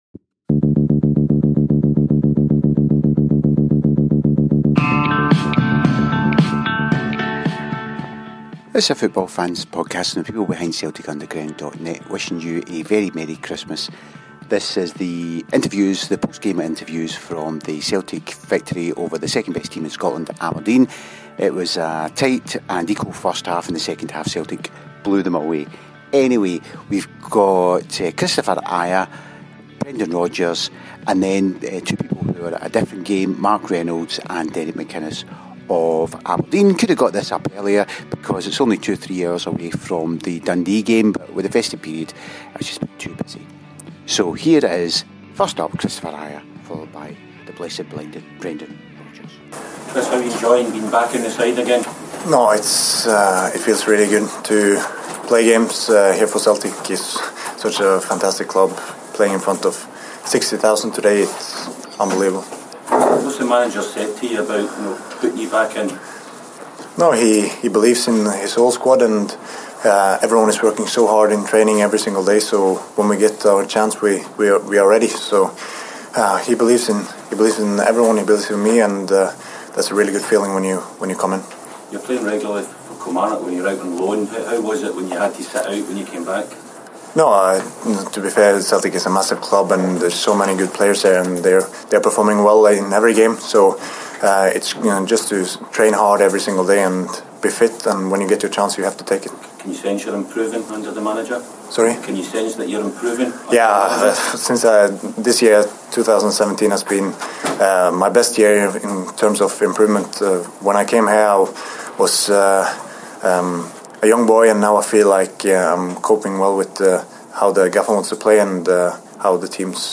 After the game Kristopher Ajer, Brendan Rodgers, Mark Reynolds and Derek McInnes spoke to the press.